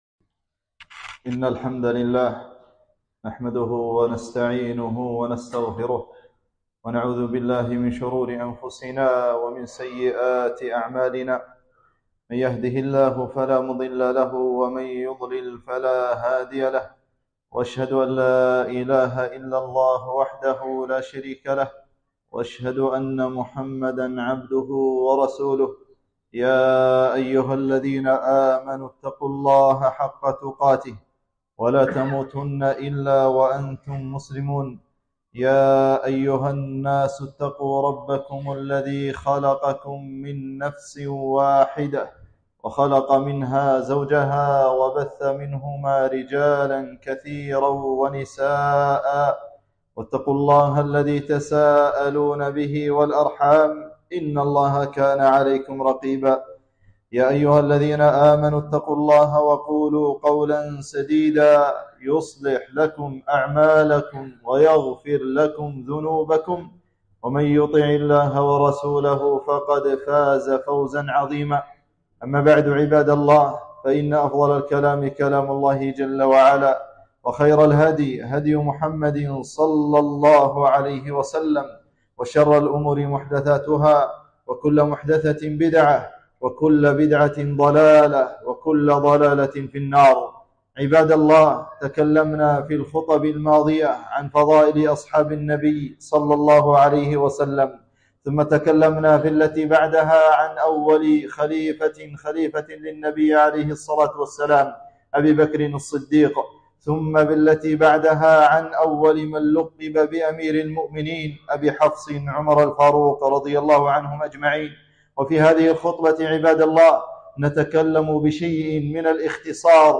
خطبة - فضائل عثمان بن عفان